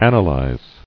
[an·a·lyse]